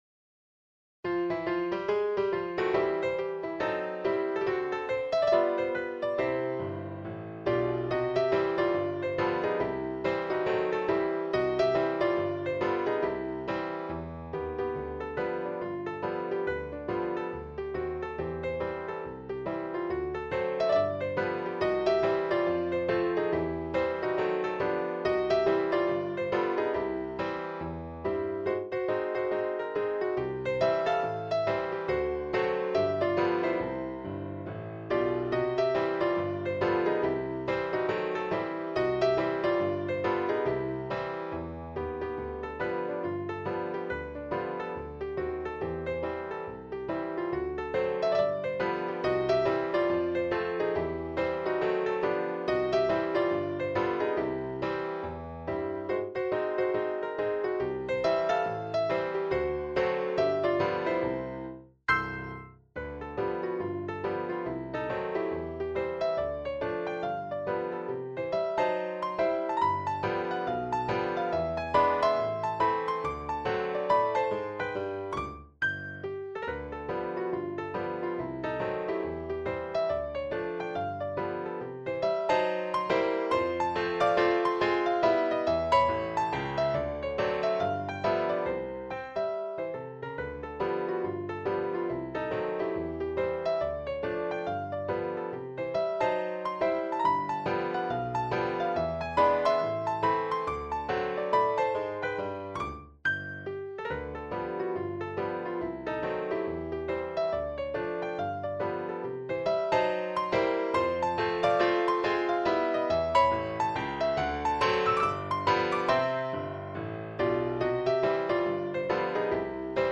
Un ragtime original inédito para piano solo
La grabación corresponde al playback generado con Sibelius.
Piano solo